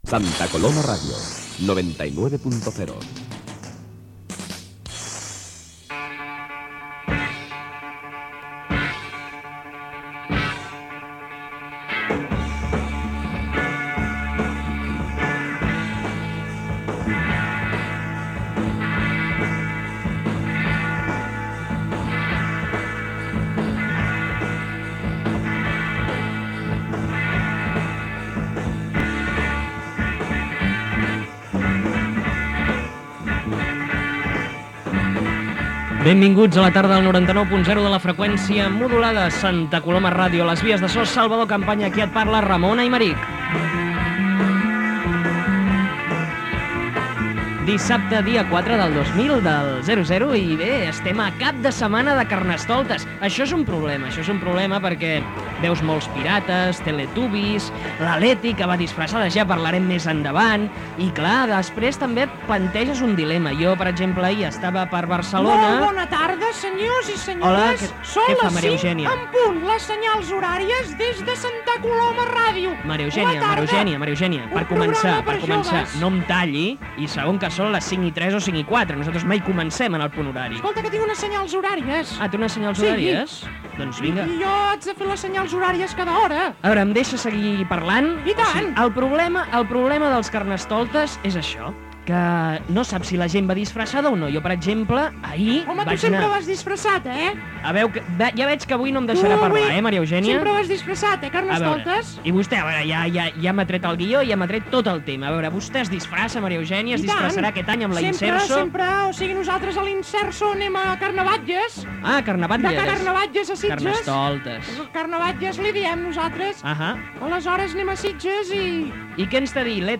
Indicatiu de la ràdio, presentació del programa amb esment al Carnaval
Entreteniment
FM